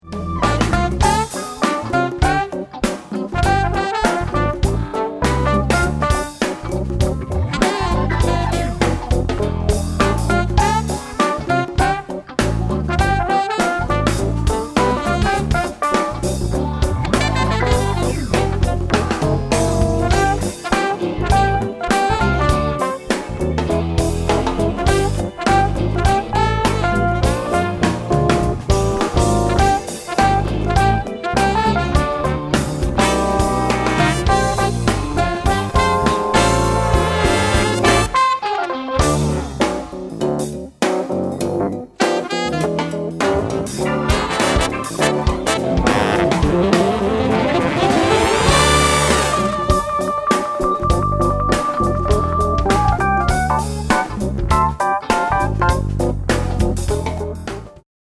7、 融合爵士（Fusion Jazz）
因此，融合爵士给人的感觉是既新鲜又流行，而又不失爵士乐的色彩。
鼓手